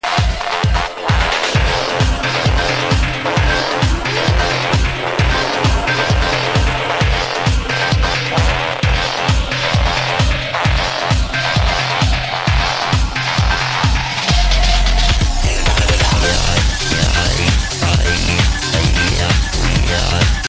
Exclamation Progressive house classic tune. should be easy